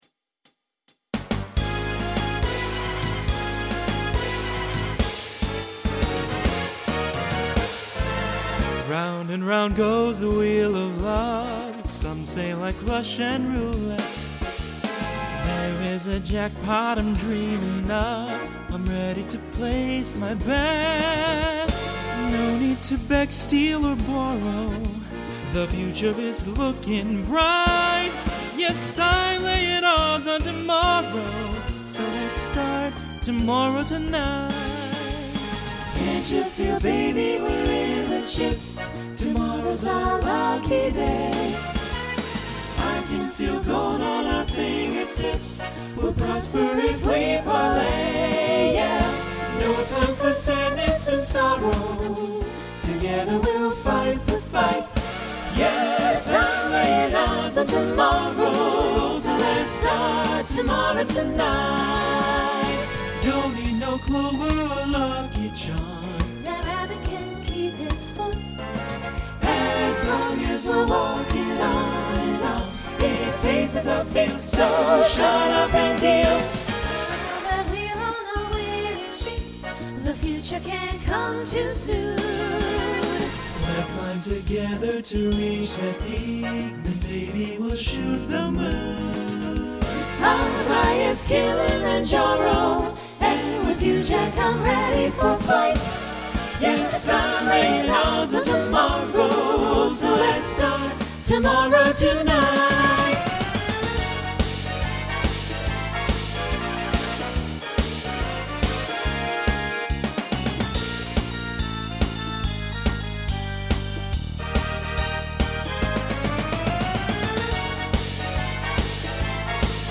Mid-tempo